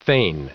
Prononciation du mot fain en anglais (fichier audio)
Prononciation du mot : fain